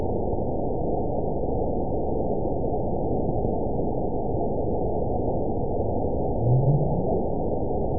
event 922663 date 02/27/25 time 09:00:39 GMT (9 months, 1 week ago) score 9.10 location TSS-AB02 detected by nrw target species NRW annotations +NRW Spectrogram: Frequency (kHz) vs. Time (s) audio not available .wav